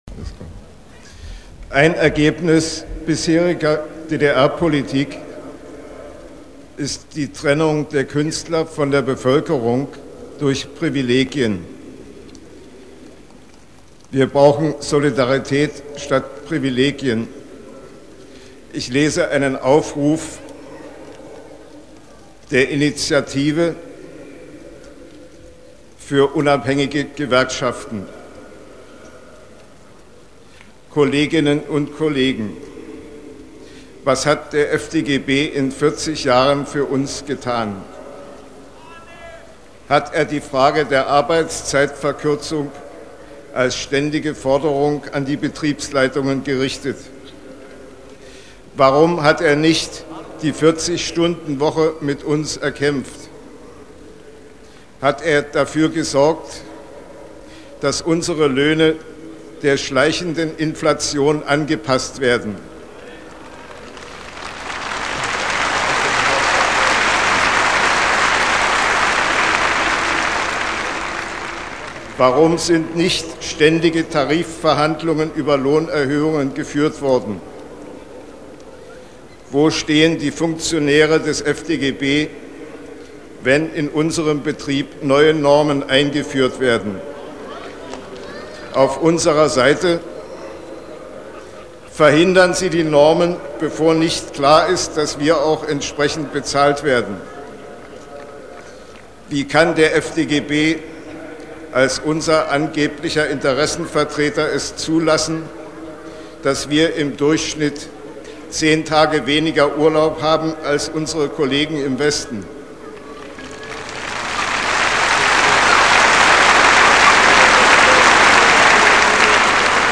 Reden vom 4.11.1989-Heiner Müller